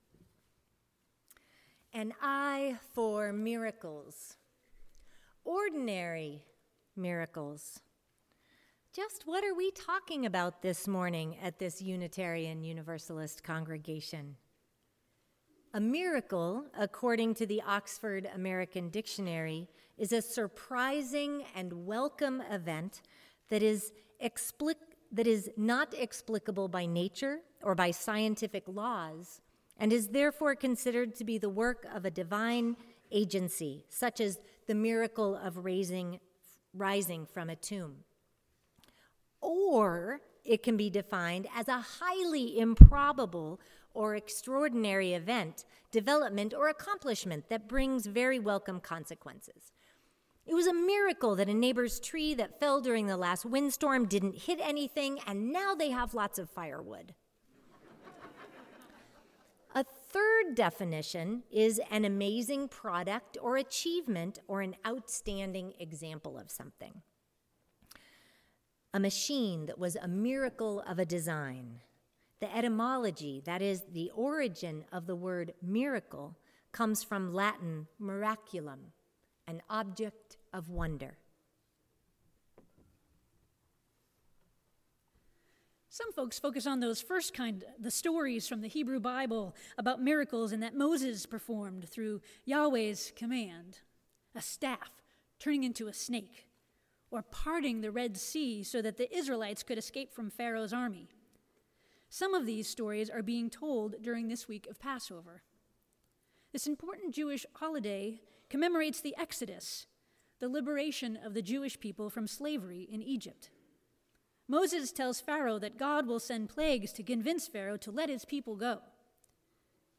Celebrate Easter Sunday, with myth and miracles, choirs singing and flowers. What does this ancient story of resurrection offer us today?